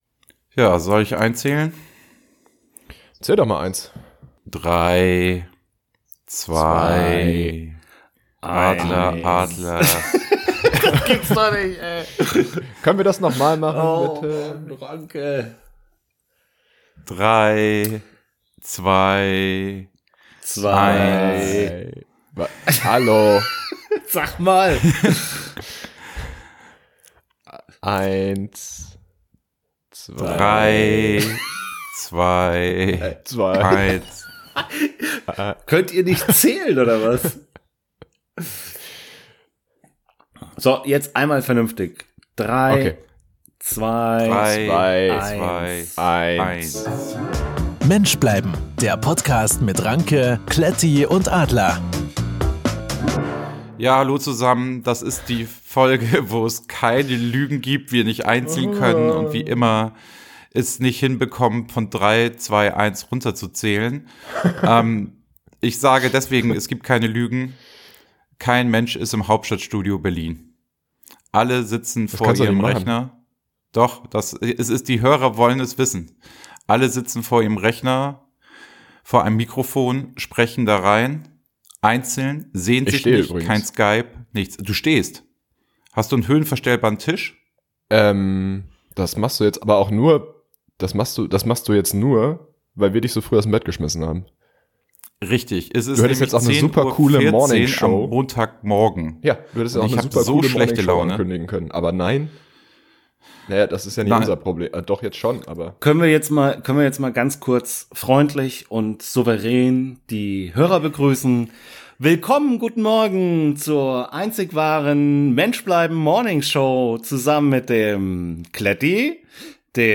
Die legendäre und einzigartige Morningshow ist zurück.
Wieso riecht Bier nach Marihuana, warum riecht kalter Kaffee viel besser, als er schmeckt und wie bekommt man den Vodka wieder aus der Milch? All diesen Fragen gehen die drei Jungs auf den Grund.